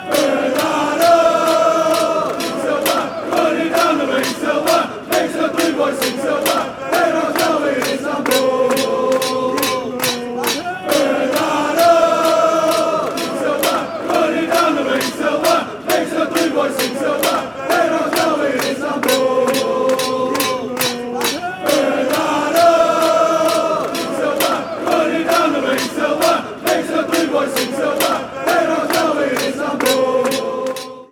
Raising the rafters at the City of Manchester stadium!